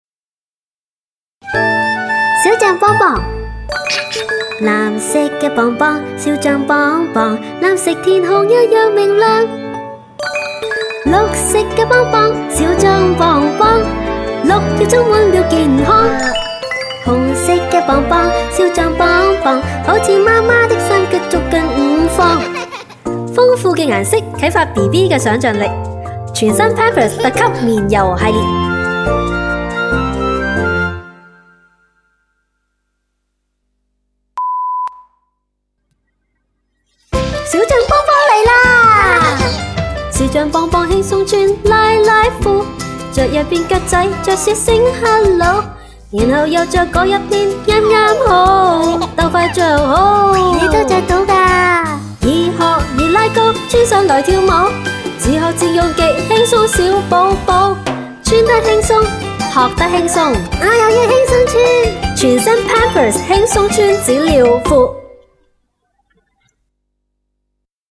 background vocalist